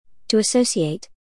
[ə’səuʃɪeɪt], [ə’səusɪeɪt][э’соушиэйт], [э’соусиэйт]ассоциировать, связывать с (кем-л. / чем-л.); присоединять